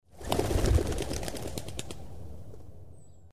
Звуки отпугивания голубей